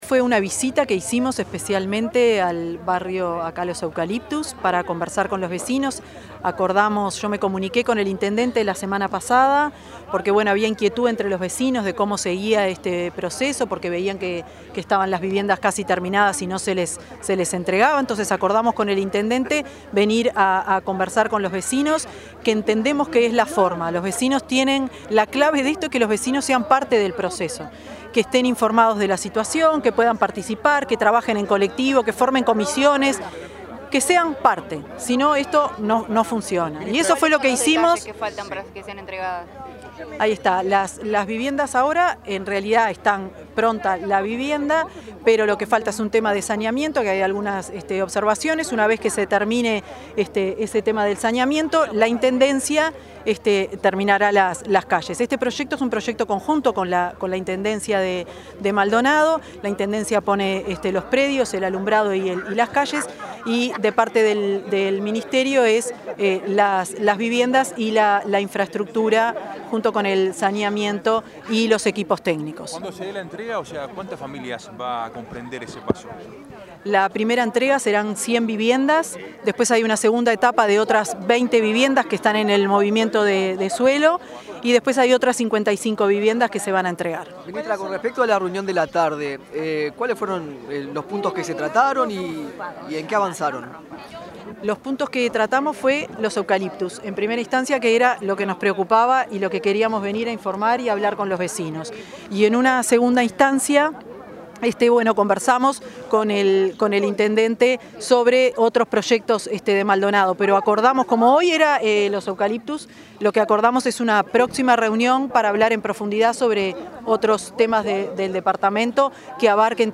Declaraciones de la ministra de Vivienda, Tamara Paseyro
Declaraciones de la ministra de Vivienda, Tamara Paseyro 08/08/2025 Compartir Facebook X Copiar enlace WhatsApp LinkedIn La titular del Ministerio de Vivienda y Ordenamiento Territorial, Tamara Paseyro, dialogó con la prensa tras visitar las obras de regularización en el asentamiento Los Eucaliptus, en Maldonado.